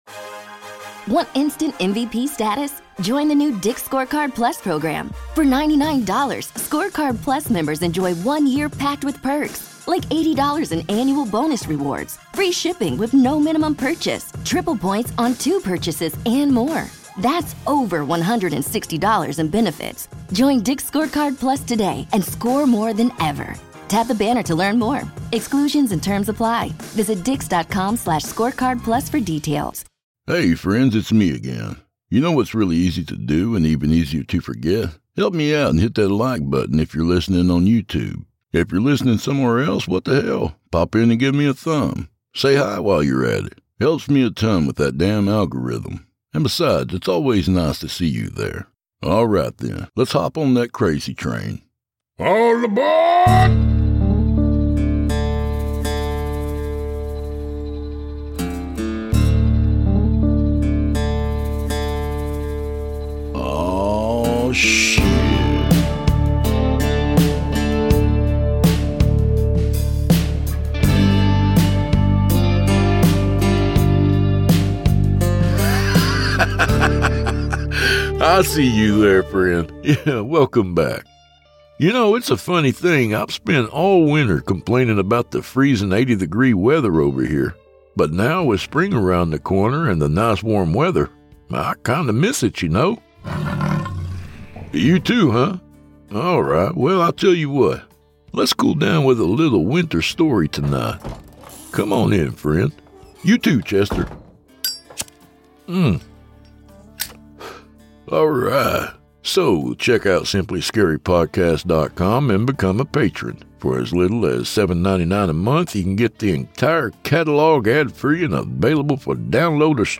A Horror Anthology and Scary Stories Podcast